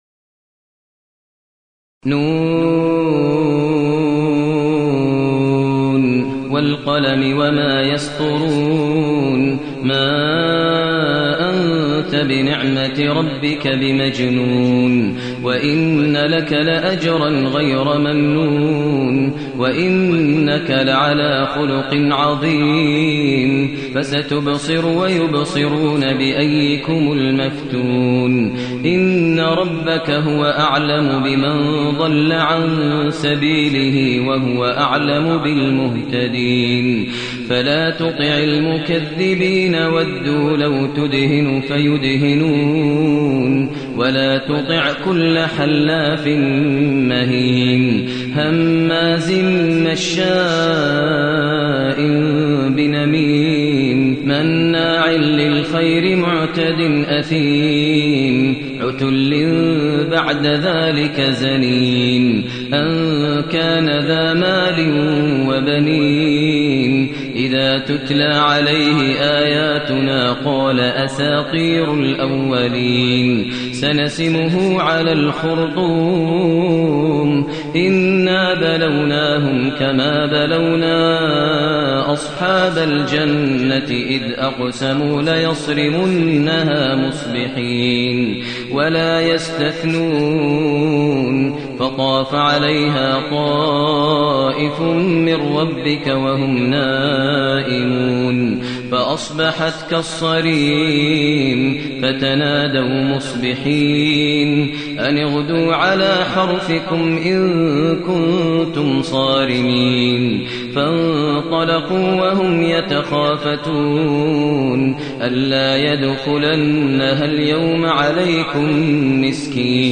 المكان: المسجد النبوي الشيخ: فضيلة الشيخ ماهر المعيقلي فضيلة الشيخ ماهر المعيقلي القلم